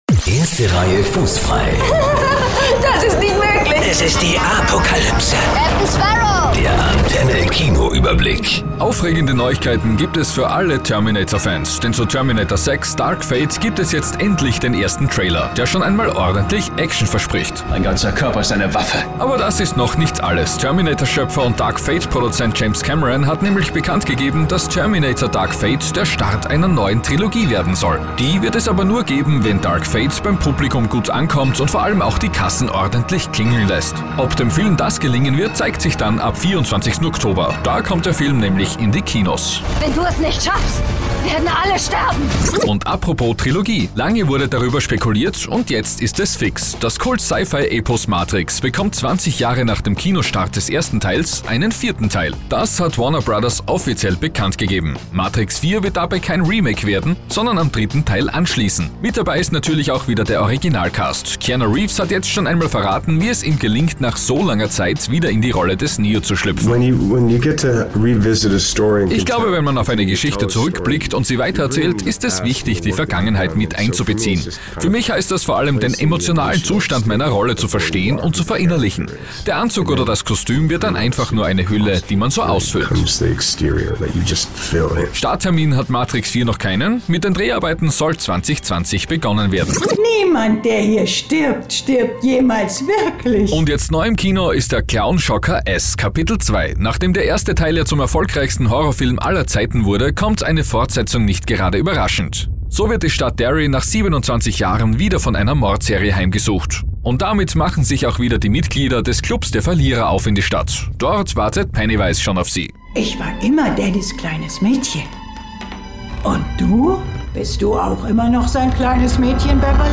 Im Radiobeitrag selbst entsteht durch den Einsatz von Star-Interviews und Filmausschnitten ein spannendes Wechselspiel zwischen Information und Unterhaltung.
Außerdem bekommen die Radiobeiträge eine sendereigene Verpackung mit eurer Station Voice und eurem Positioning.
Hier findet ihr einige Produktionen, die wir für diverse Radiostationen in den soundlarge-Studios realisiert haben: